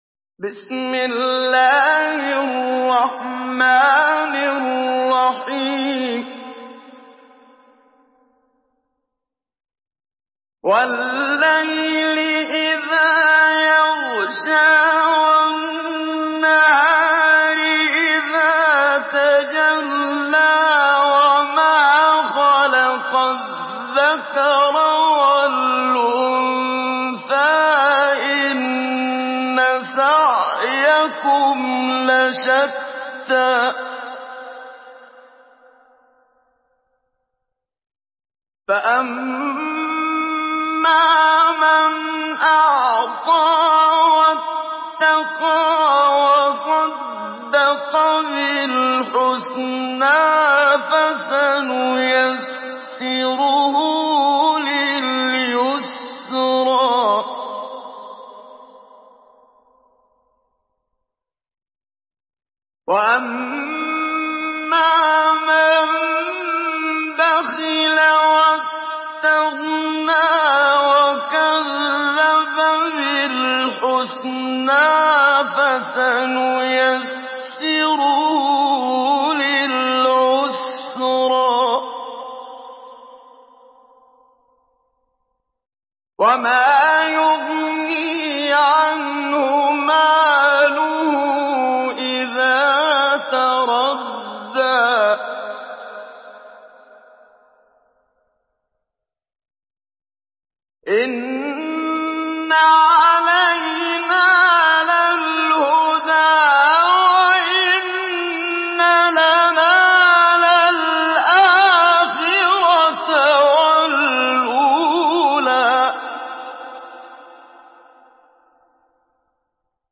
Surah আল-লাইল MP3 in the Voice of Muhammad Siddiq Minshawi Mujawwad in Hafs Narration
Surah আল-লাইল MP3 by Muhammad Siddiq Minshawi Mujawwad in Hafs An Asim narration.